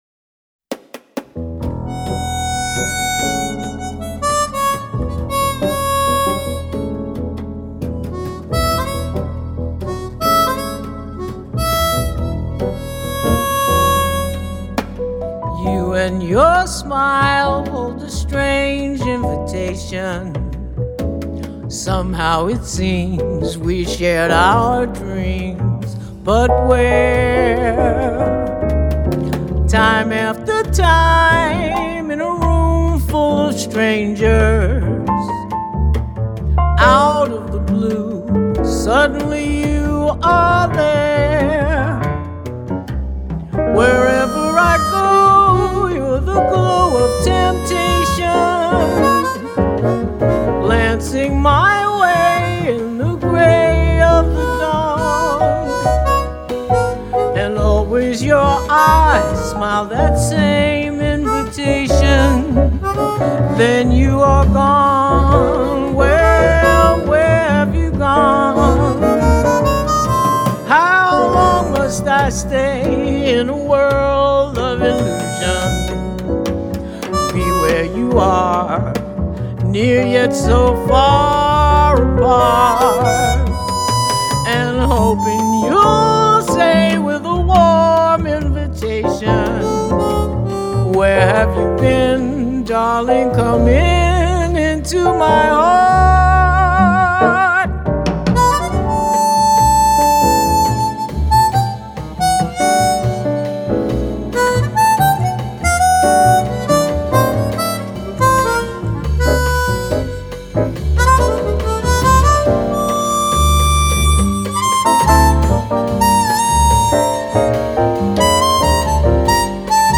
quintet
with longer notes revealing her rich vocal texture.
FILE: Jazz Vocals